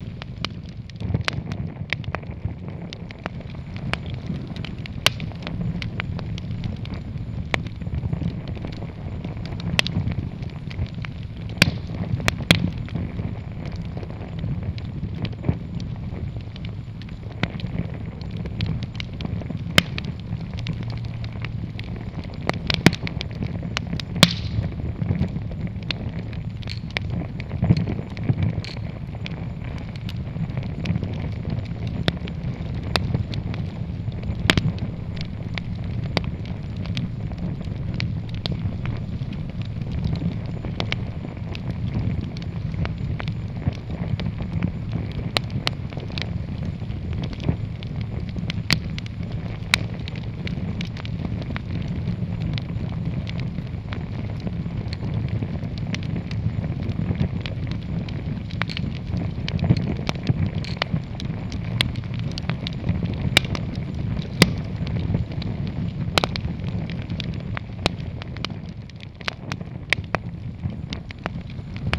firecamp.wav